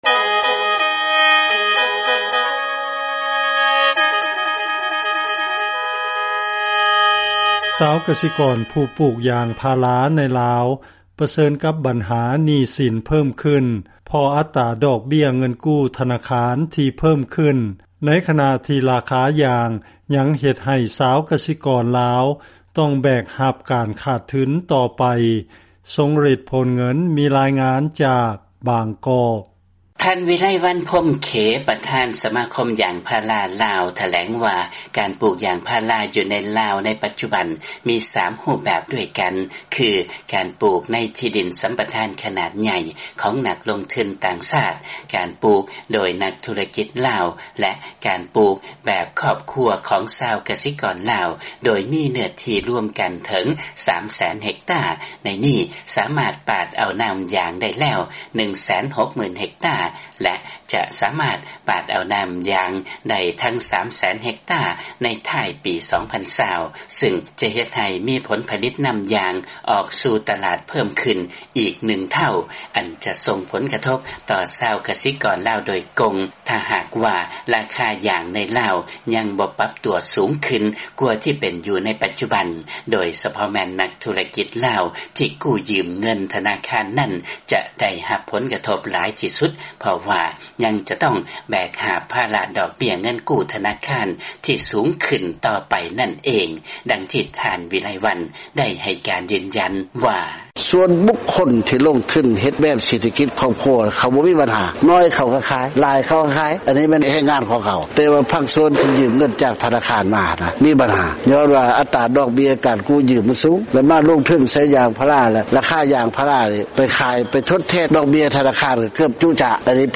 ມີລາຍງານຈາກບາງກອກ